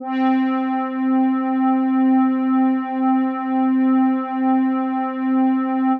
C4_trance_pad_1.wav